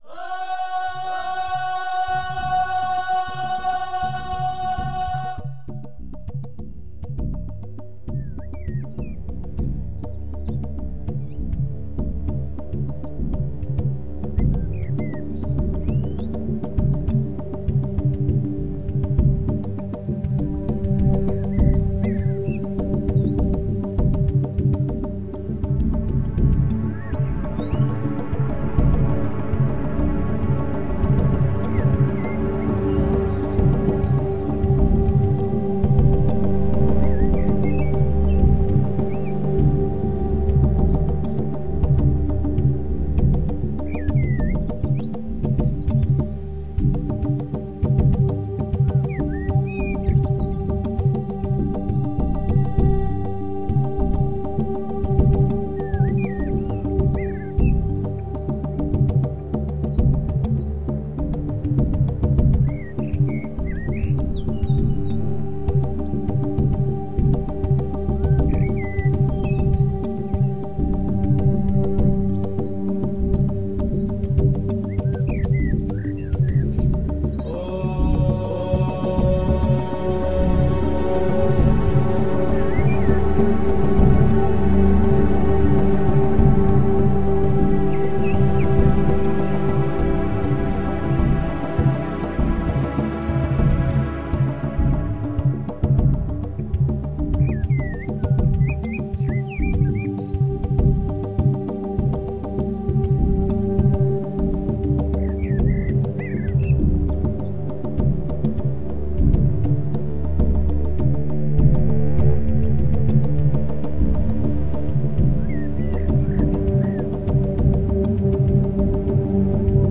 Ambient electronic and neoclassical soundscapes.
Tagged as: New Age, World, African influenced